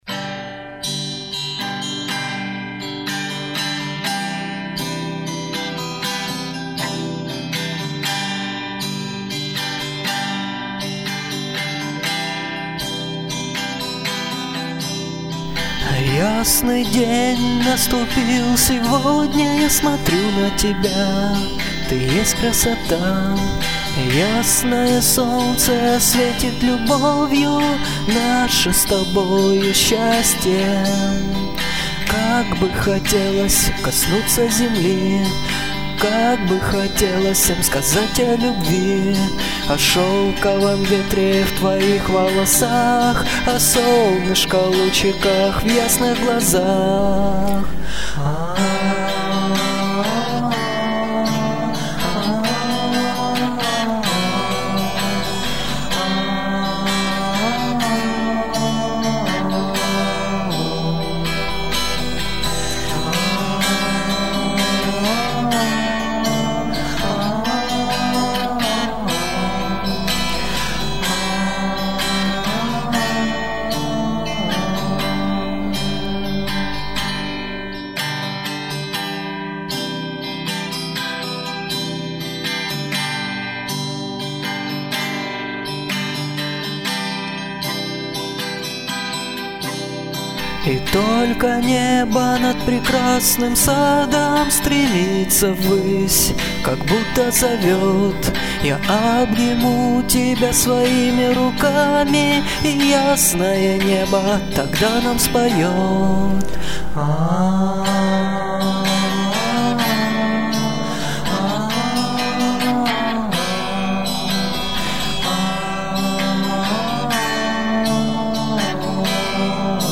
Слова, музыка, вокал - все мое родное :)
Писал все дома на комп. Играл на гитаре и синтезаторе. Пел в гарнитуру.